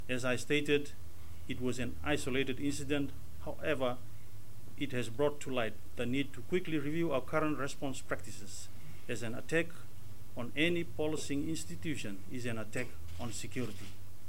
Acting Police Commissioner, Juki Fong Chew